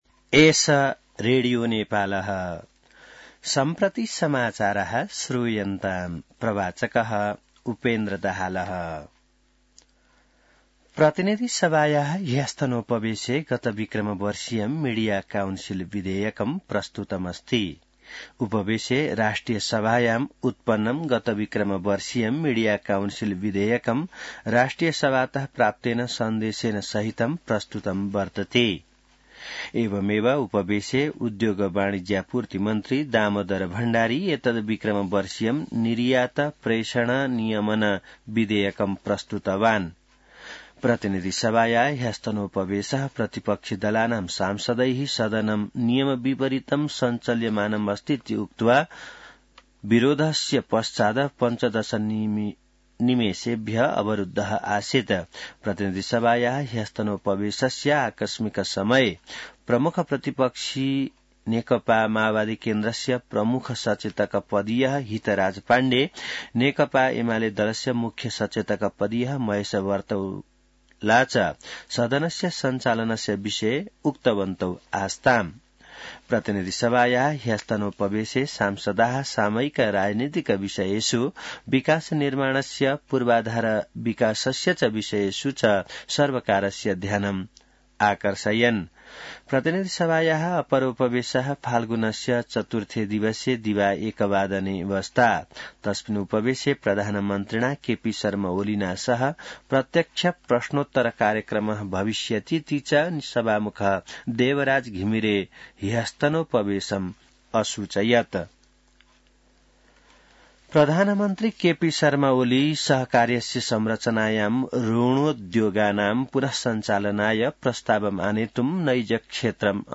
संस्कृत समाचार : १ फागुन , २०८१